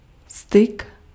Ääntäminen
IPA : /kʌˌmjunɪˈkeɪʃən/